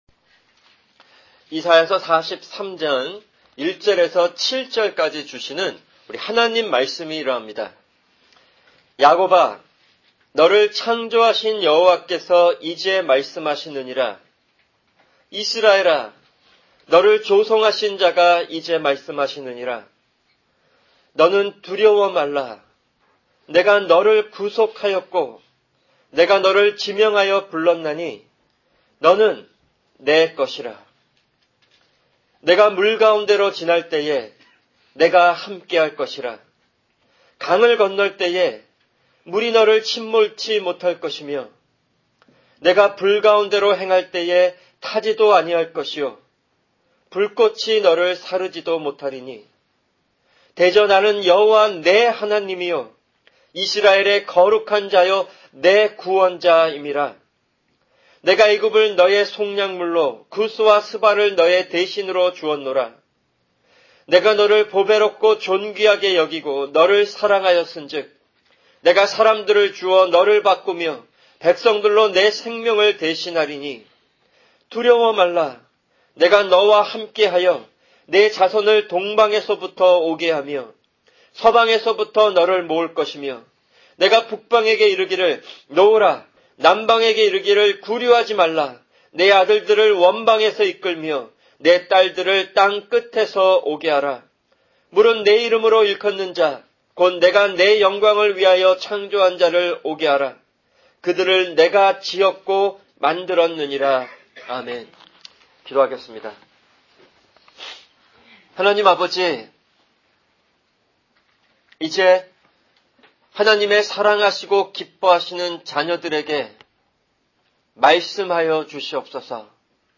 [주일 설교] 이사야43:1-7